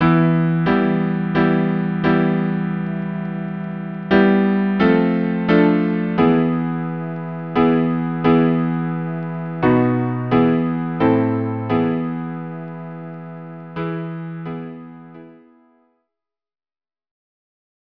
Instrumentation: Violin 1; Violin 2; Viola; Cello